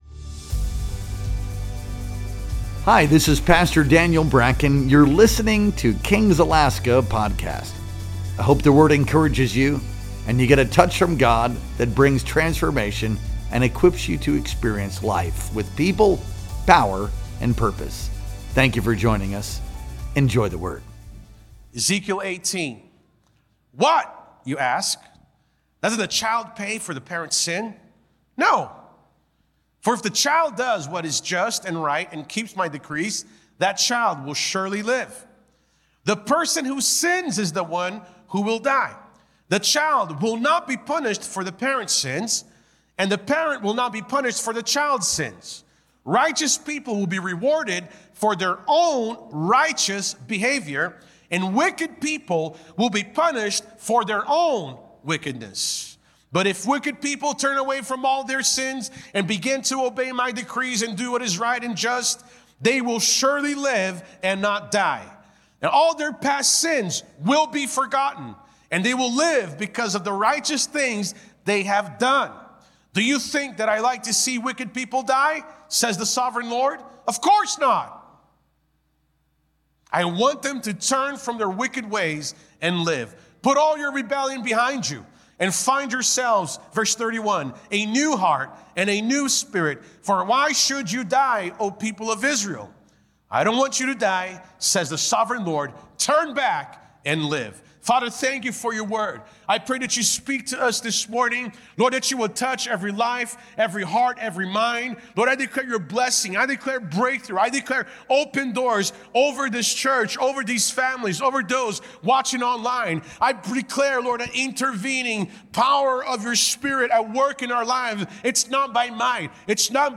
Our Sunday Morning Worship Experience streamed live on December 28th, 2025.